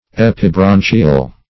Search Result for " epibranchial" : The Collaborative International Dictionary of English v.0.48: Epibranchial \Ep`i*bran"chi*al\, a. [Pref. epi- + branchial.]